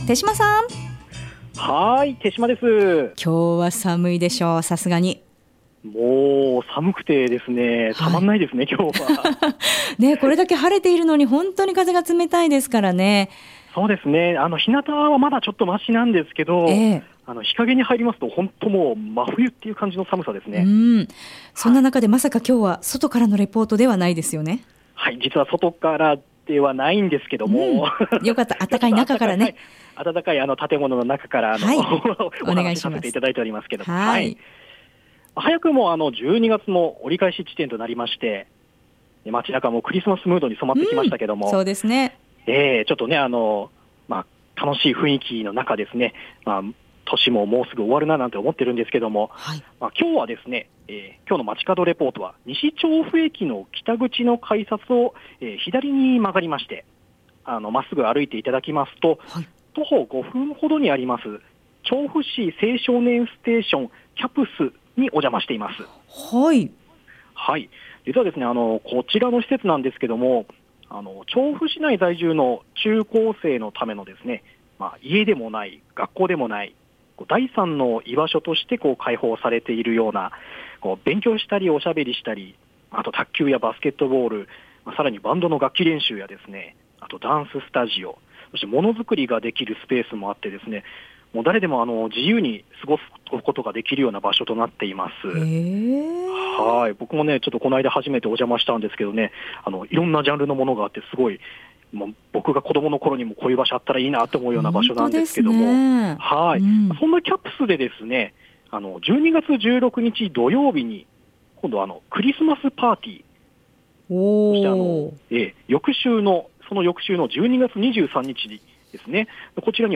今回の放送音声はコチラ↓ 午後のカフェテラス 街角レポート 2017-12-14(木) クリスマスパーティは施設を利用している中高生が中心となって、 企画の立案から実行までを委員会を立ち上げて行っているとのことです。